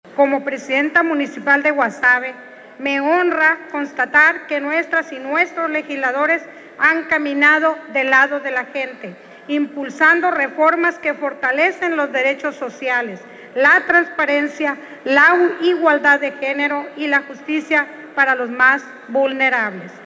Guasave, Sinaloa 18 de octubre de 2025.- Atendiendo la invitación de los representantes del Partido Verde Ecologista de México, el gobernador Rubén Rocha Moya, acudió, al primer informe de labores de la y el diputado del grupo parlamentario, por los distritos 07 y 08, quienes, en un acto de rendición de cuentas ante la sociedad compartieron los resultados de su trabajo del último año en el Congreso del Estado.